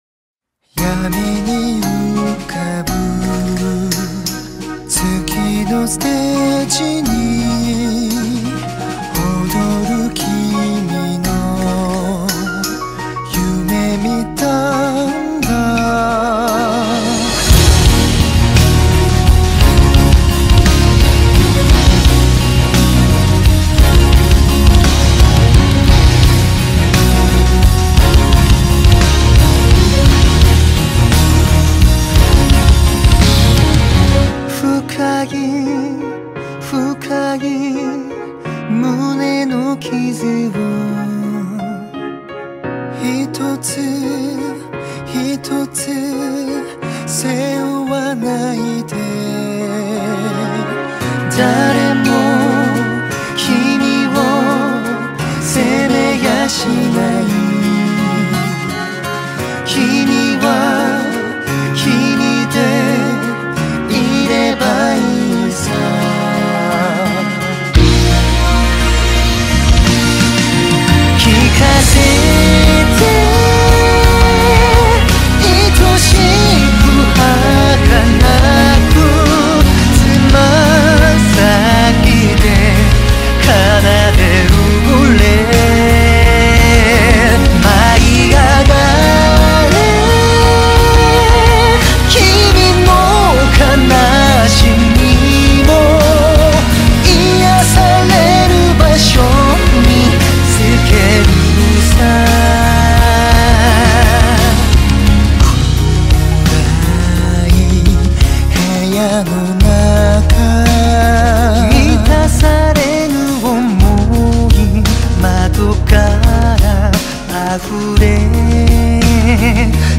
▼2人版
５人でレコーディングした原曲の音源と